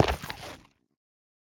Minecraft Version Minecraft Version latest Latest Release | Latest Snapshot latest / assets / minecraft / sounds / block / shroomlight / step4.ogg Compare With Compare With Latest Release | Latest Snapshot
step4.ogg